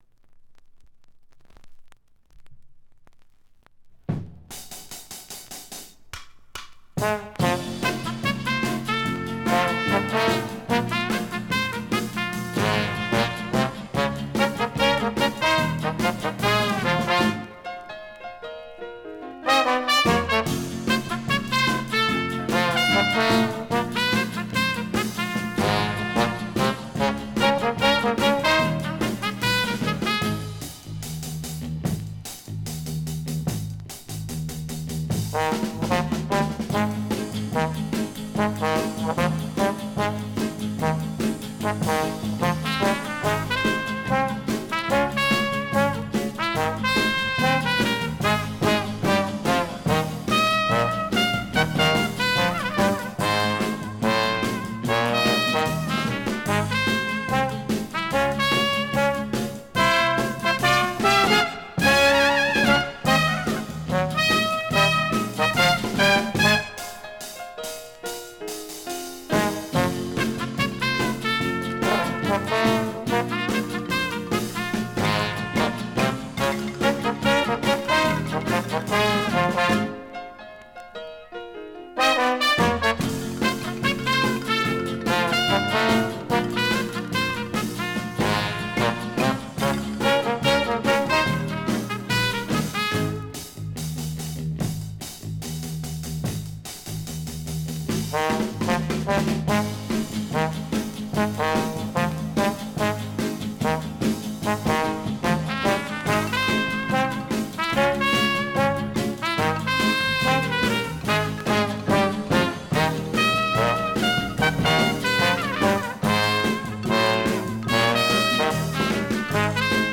Semi-Soft Music in Tijuana Style